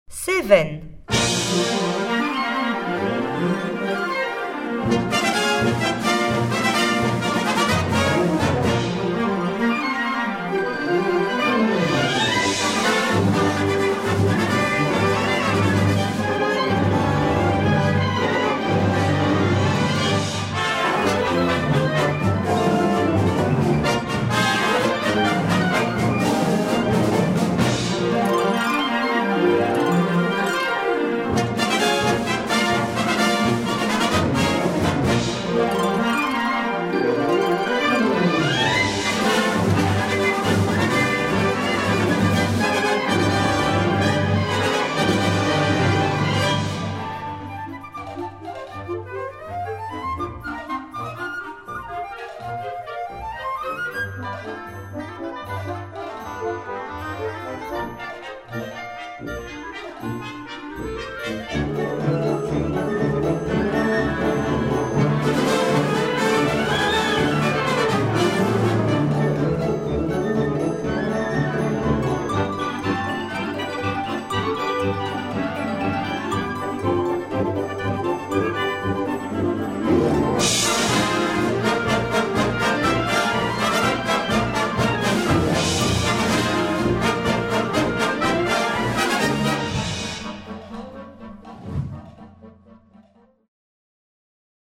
Répertoire pour Orchestre